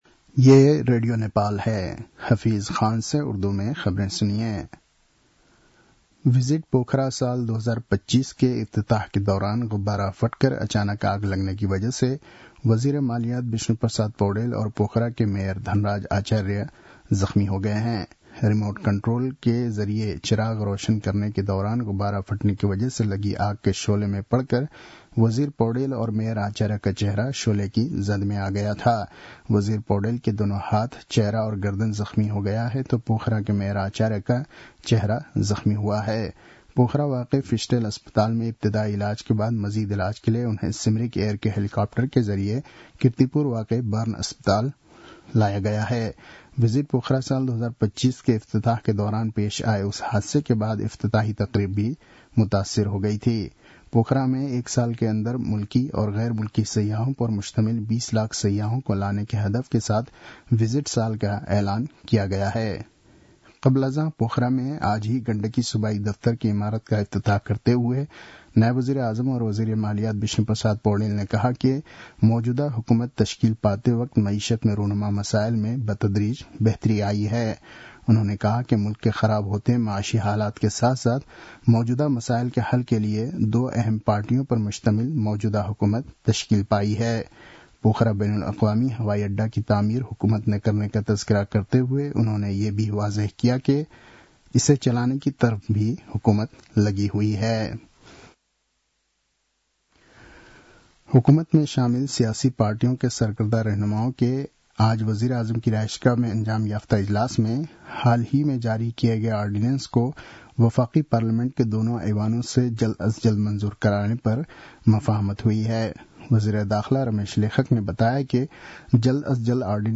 उर्दु भाषामा समाचार : ४ फागुन , २०८१